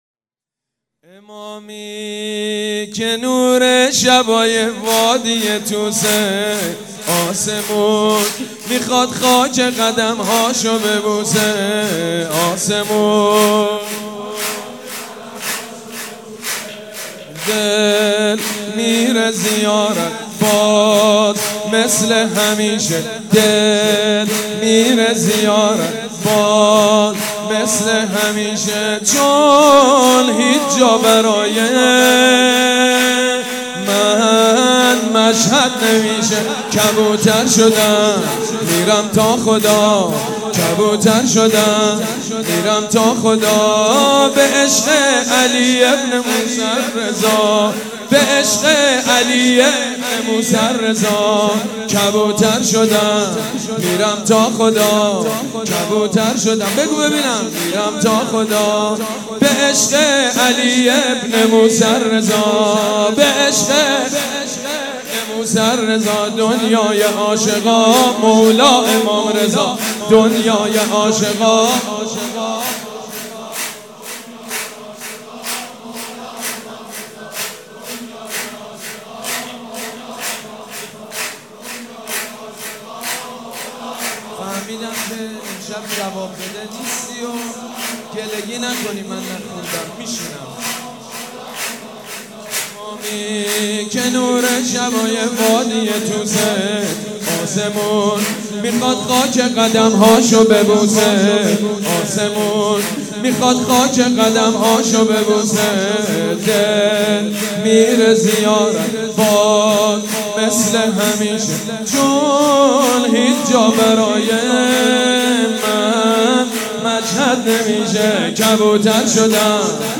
سرود: دل میره زیارت باز مثل همیشه